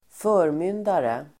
Uttal: [²f'örmyn:dare el. förm'yn:dare]